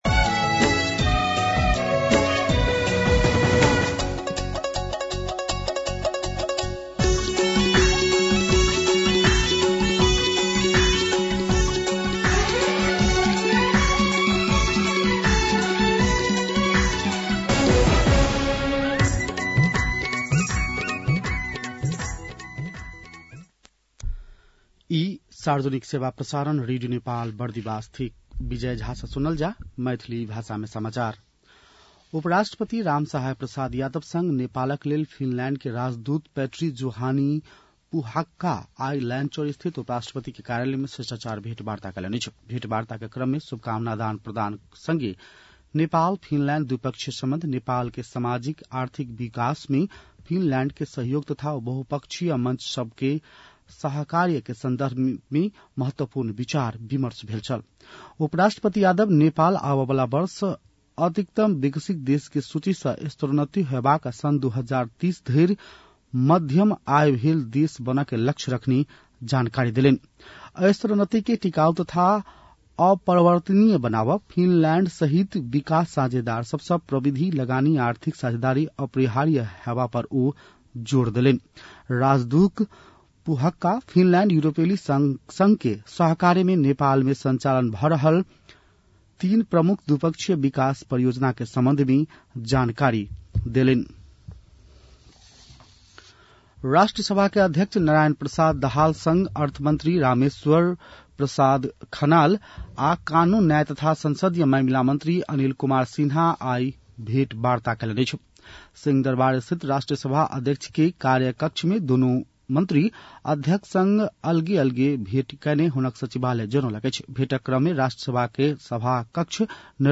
An online outlet of Nepal's national radio broadcaster
मैथिली भाषामा समाचार : १७ मंसिर , २०८२